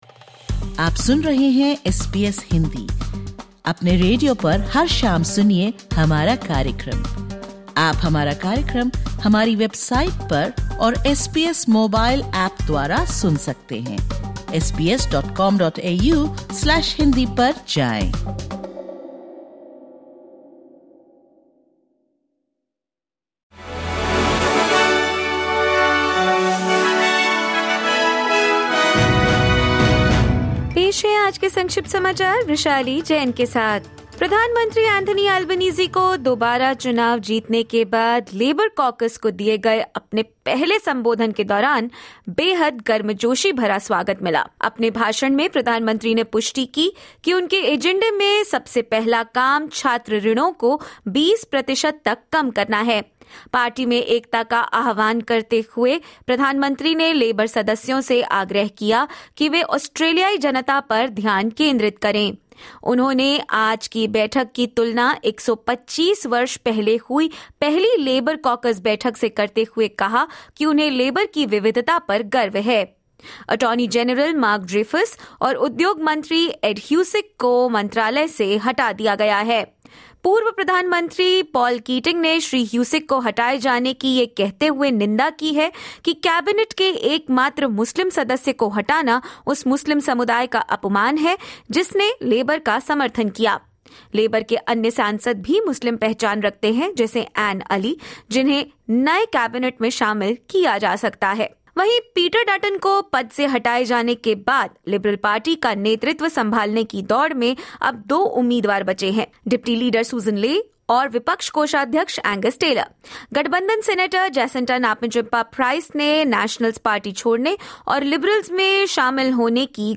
Listen to the top News of 09/05/2025 from Australia in Hindi.